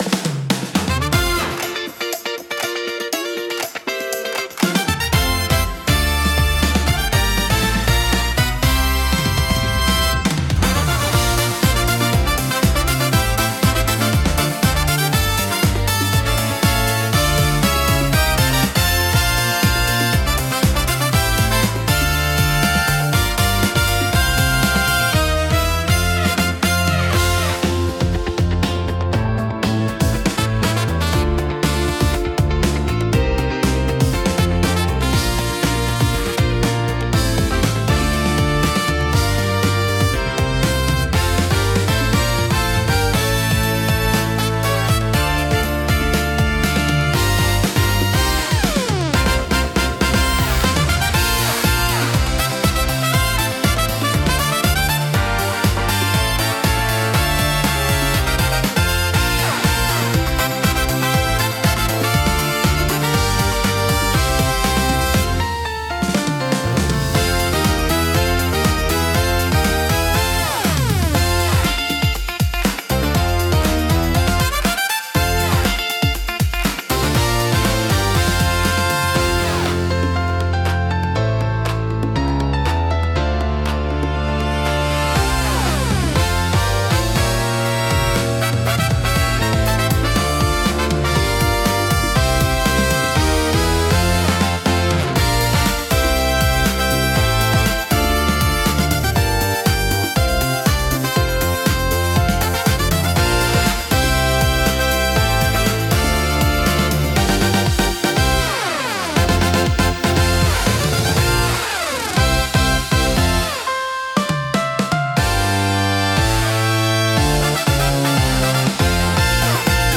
賑やかでフレンドリーな環境を求めるシーンにぴったりのジャンルです。